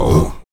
Index of /90_sSampleCDs/Roland LCDP11 Africa VOL-1/VOX_Afro Chants/VOX_Ah Oos
VOX AHOO E00.wav